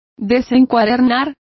Complete with pronunciation of the translation of unbound.